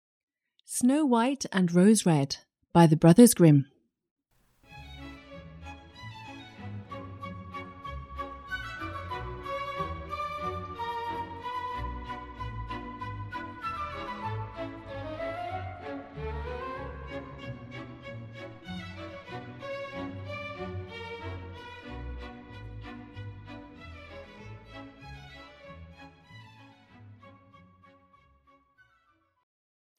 Audiobook Snow-White and Rose-Red is a German fairy tale written by Brothers Grimm.
Ukázka z knihy